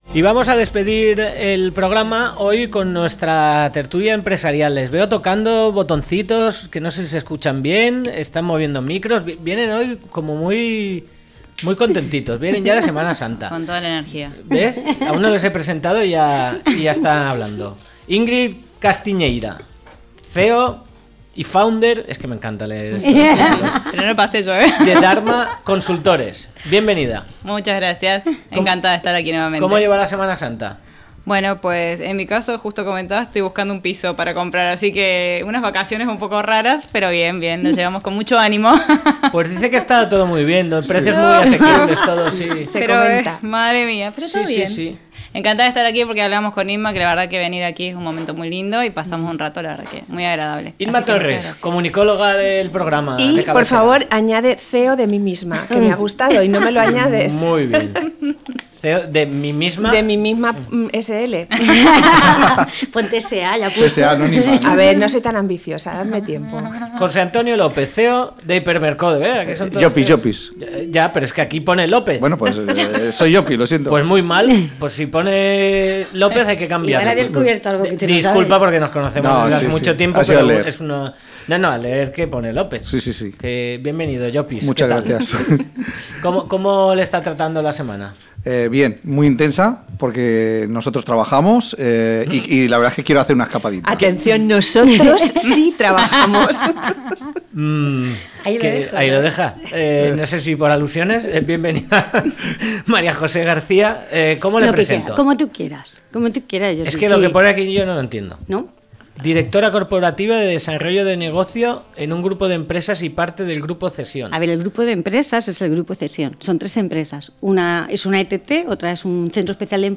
TERTULIA EMPRESARIAL